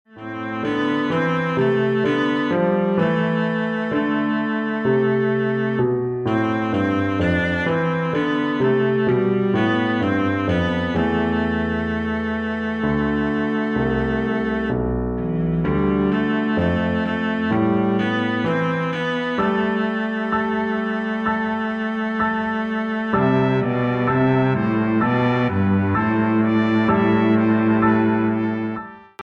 incidental music
Here is a short excerpt from his “Dies Irae Variations” played on synthesized cello and piano; you can imagine how much more powerful and lyrical this will be on real instruments.